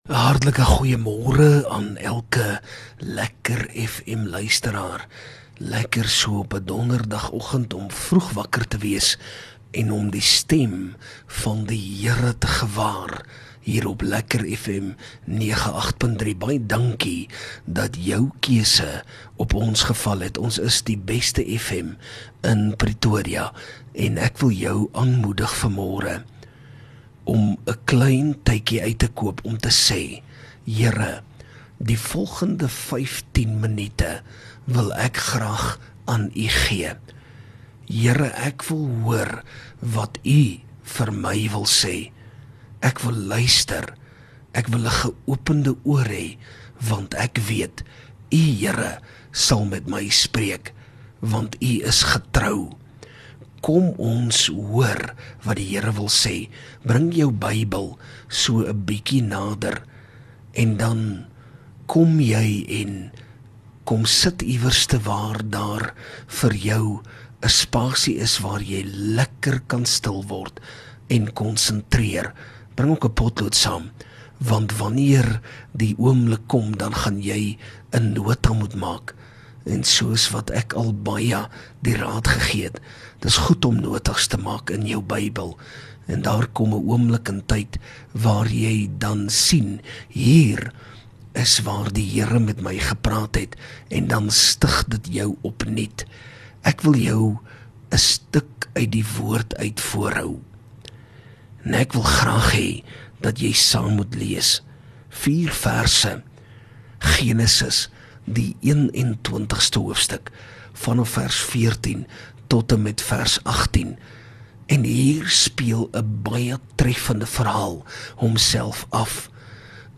LEKKER FM | Oggendoordenkings